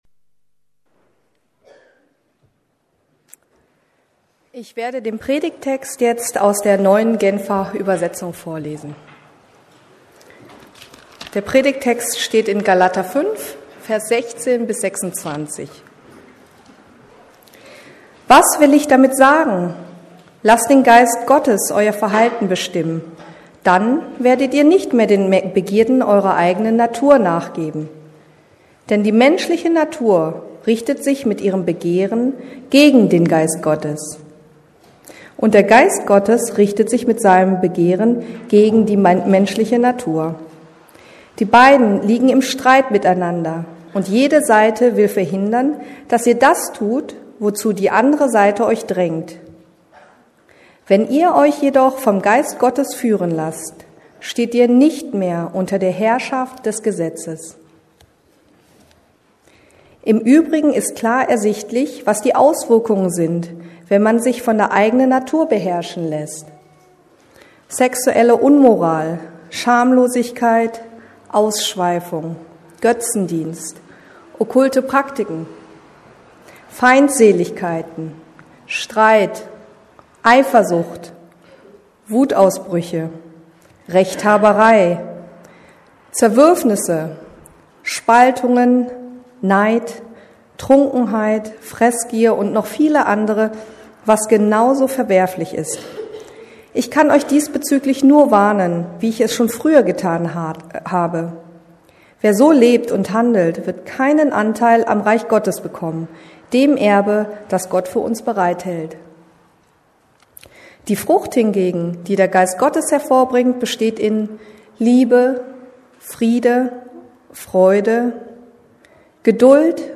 Der Heilige Geist in Aktion ~ Predigten der LUKAS GEMEINDE Podcast